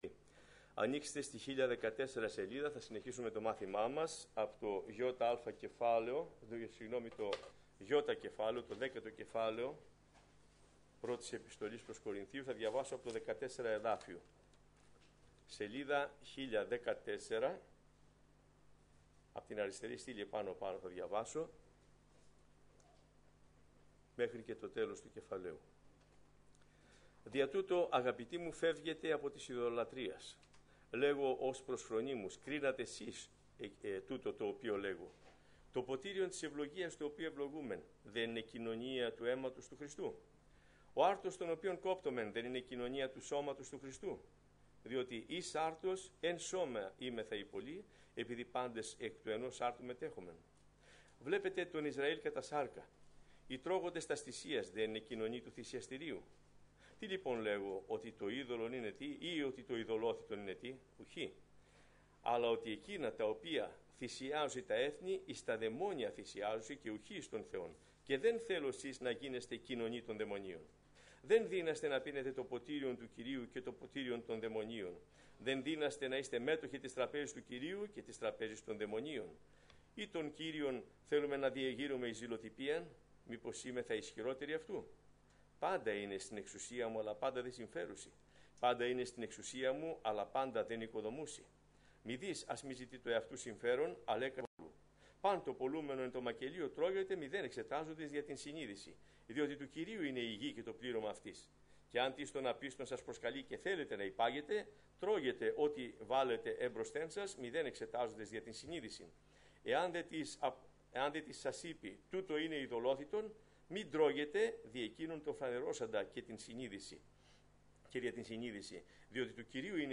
Σειρά: Μαθήματα